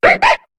Cri de Zorua dans Pokémon HOME.